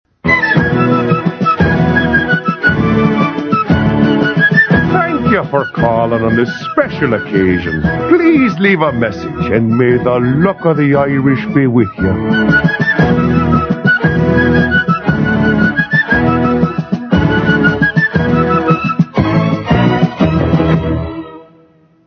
Phonies Holiday Telephone Answering Machine Messages